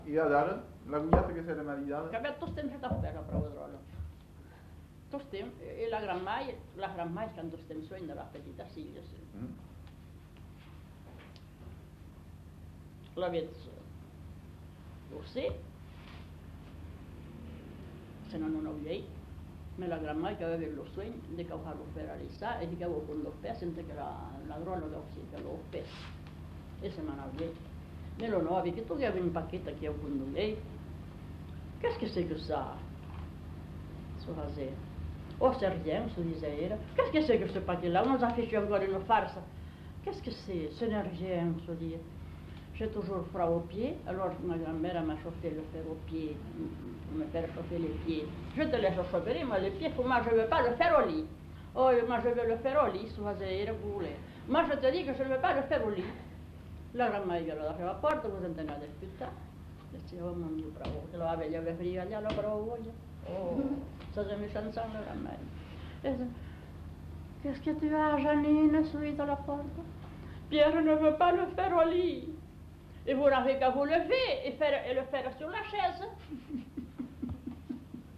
Aire culturelle : Marsan
Genre : conte-légende-récit
Effectif : 1
Type de voix : voix de femme
Production du son : parlé